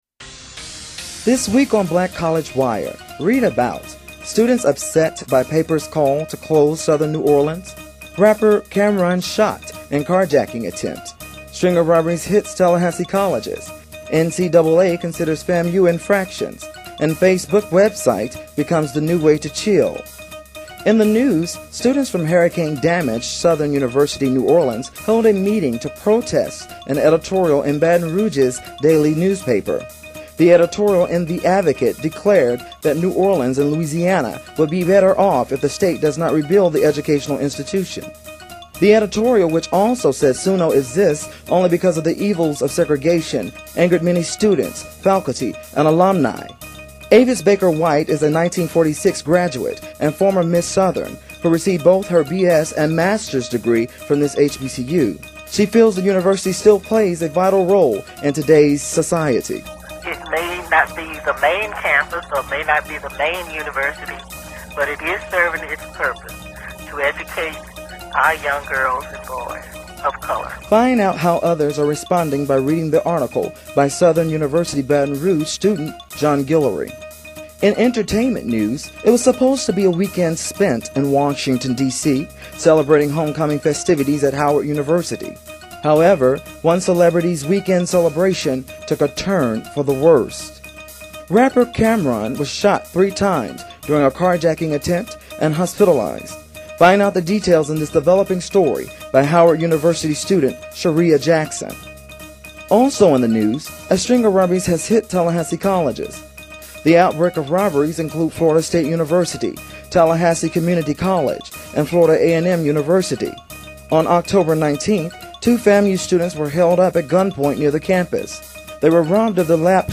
News Summary of the Week